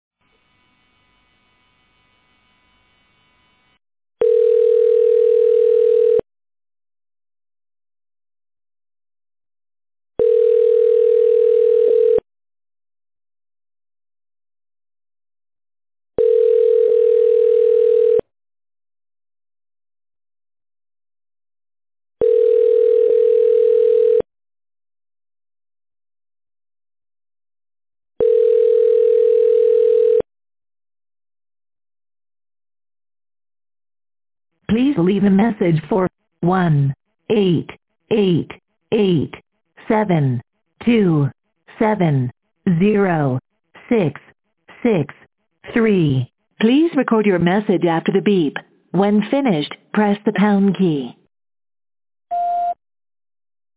I think I spooked them and they’ve turned a primitive generic voicemail on.